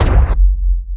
explosion_enemy.wav